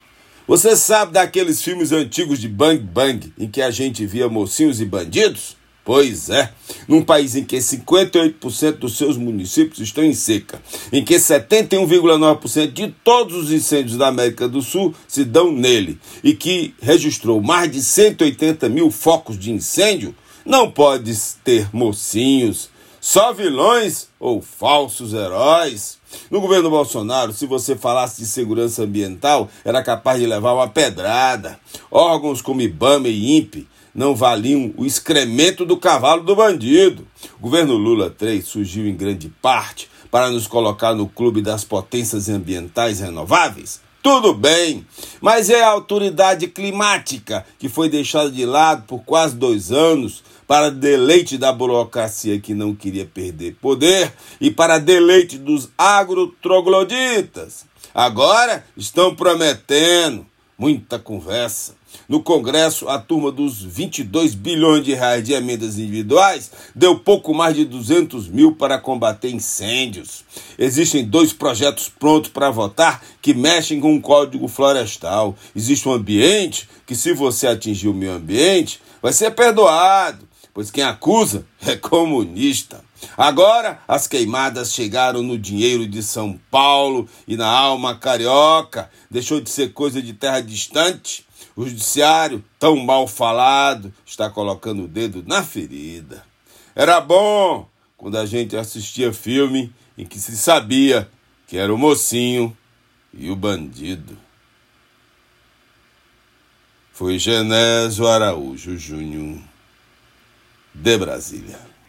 Comentário desta segunda-feira (16/09/24)
direto de Brasília.